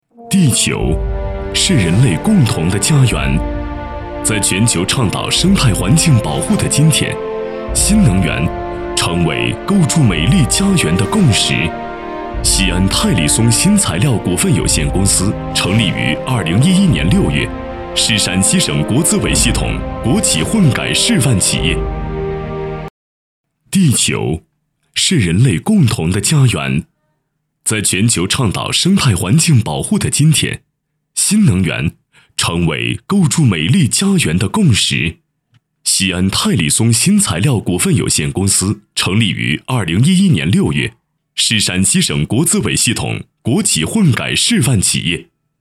专题 陕西泰力